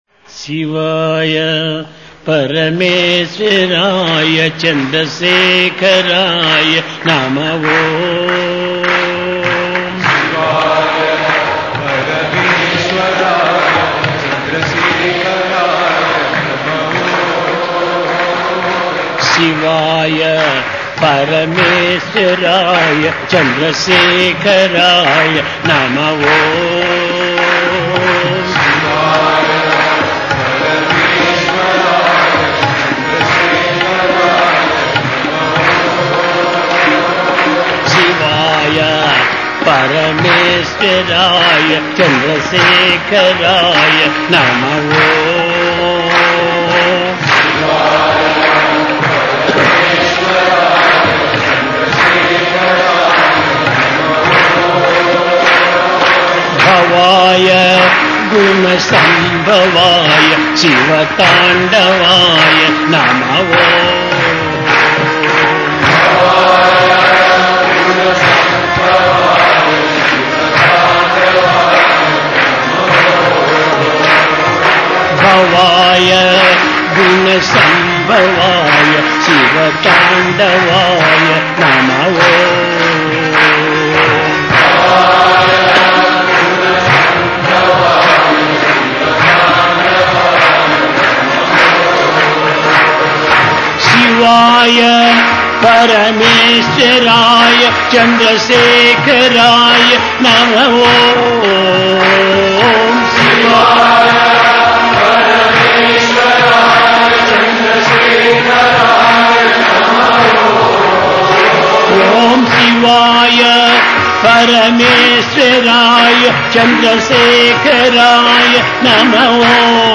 ~ Bhajan as Saadhana ~
by Sathya Sai Baba